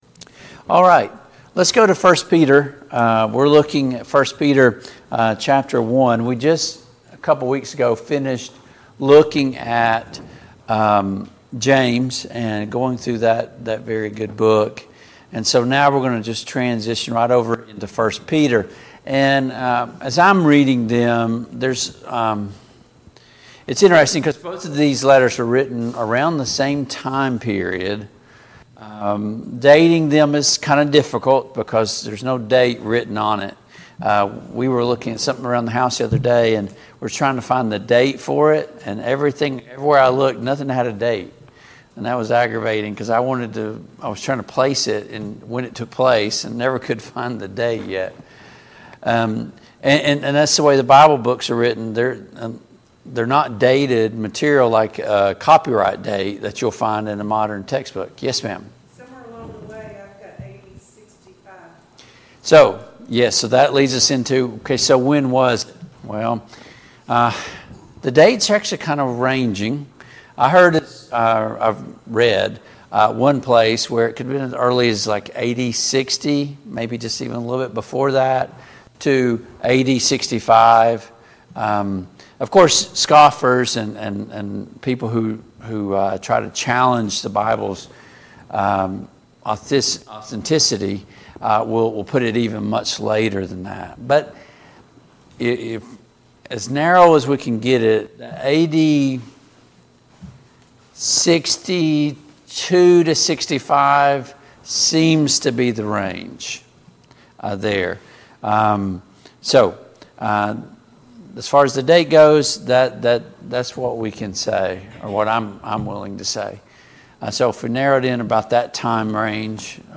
1 Peter 1:1-5 Service Type: Family Bible Hour Topics: Introduction to 1 Peter « How can I know I’m saved?